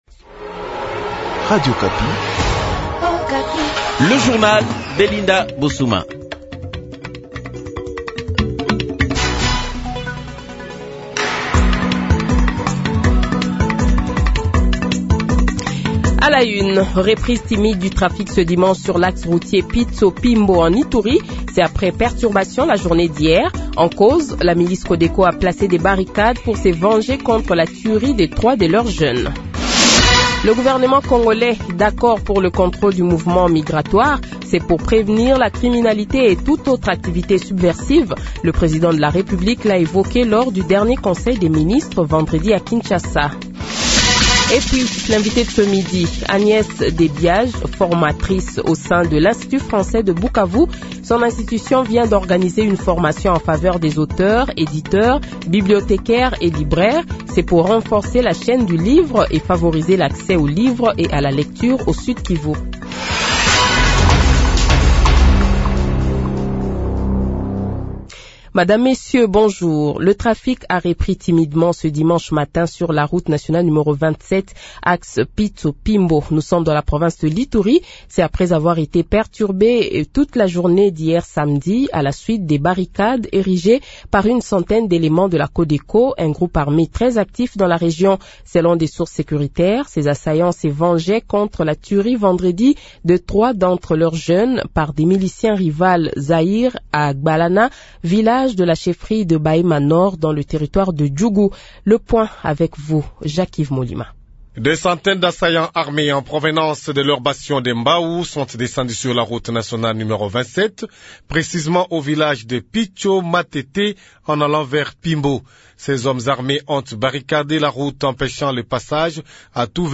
Le Journal de 12h, 15 Septembre 2024 :